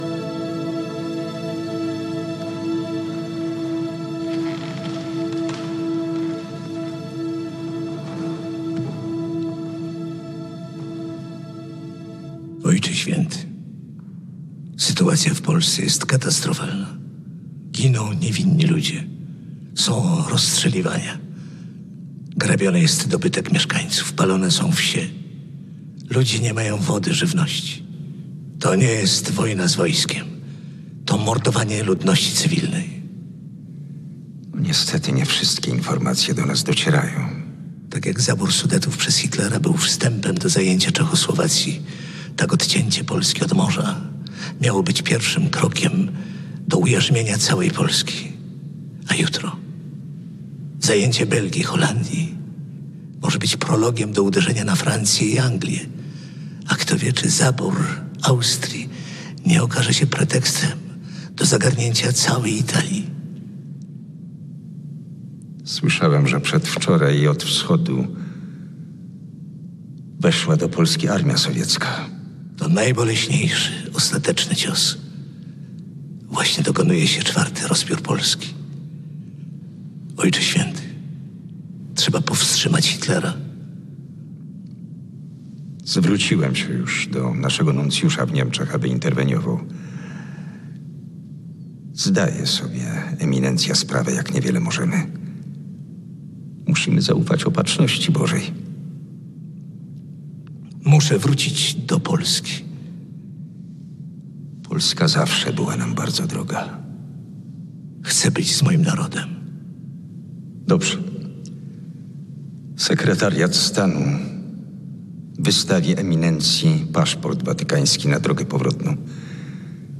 Henryk Talar jako prymas August Hlond
Wojciech Wysocki papież Pius XII
Scena z filmu.